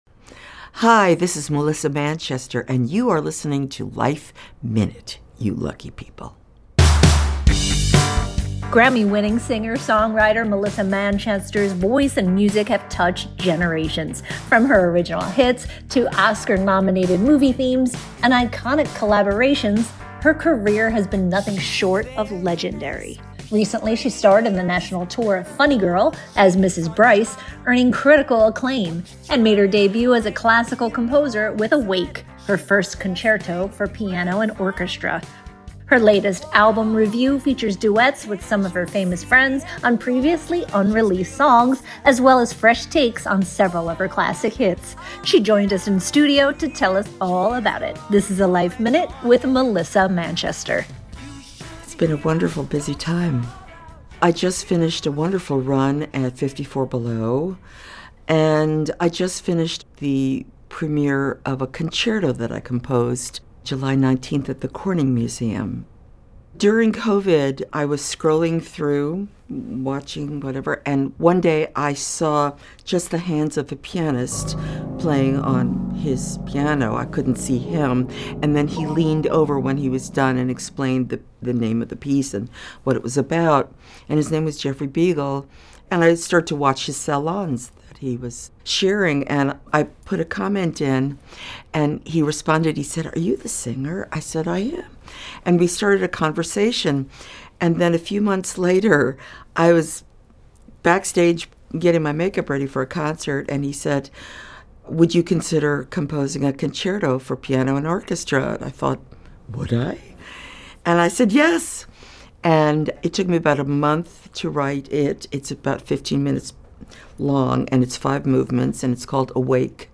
Her latest album, RE:VIEW features duets with some of her famous friends on previously unreleased songs, as well as fresh takes on several of her classic hits. She joined us in studio to tell us all about it...